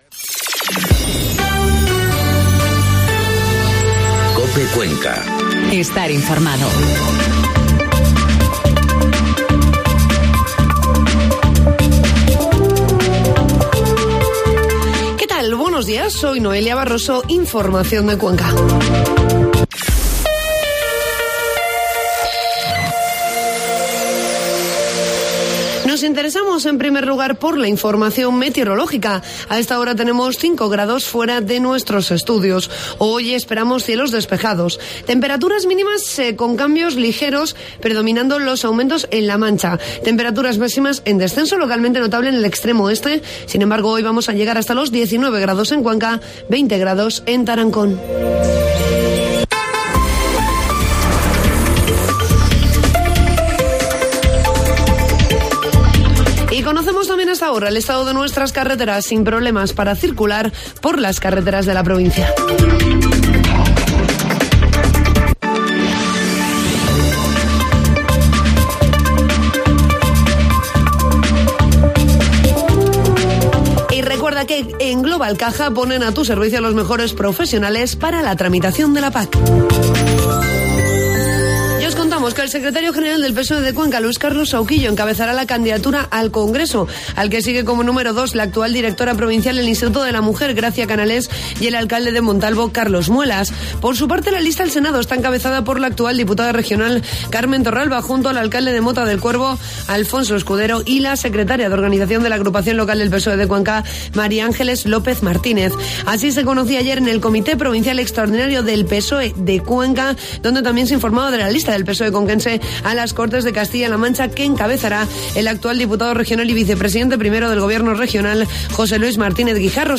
Informativo matinal COPE Cuenca 11 de marzo